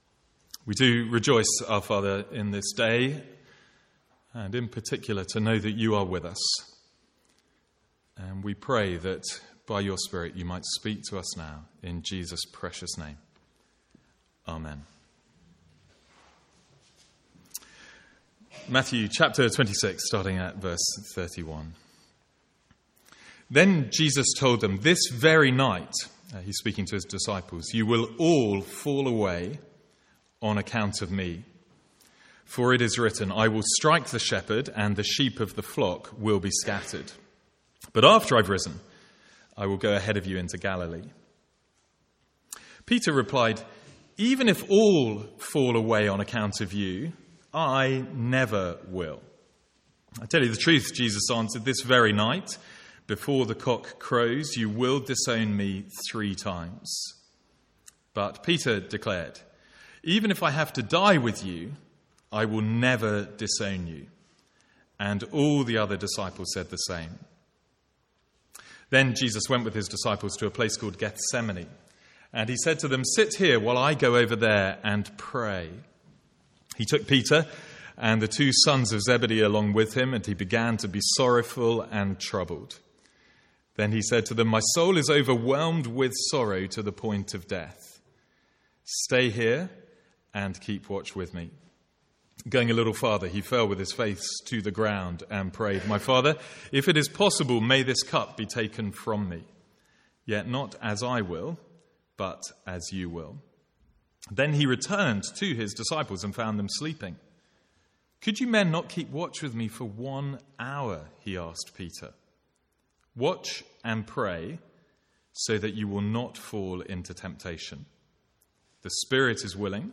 Sermons | St Andrews Free Church
From the Sunday morning series in Matthew.